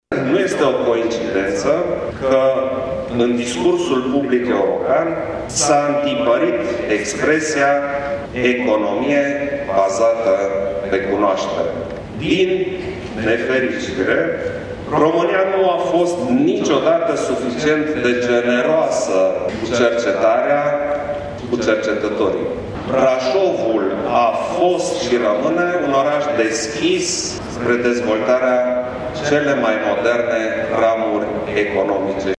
Afirmația a fost făcută de președintele Klaus Iohannis, aflat în vizită în județul Brașov.